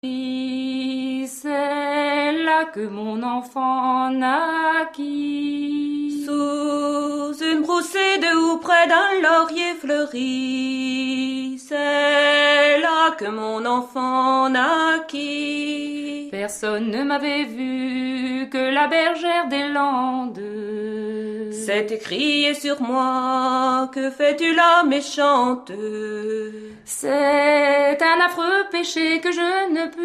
Musique : Traditionnel
Origine : Bretagne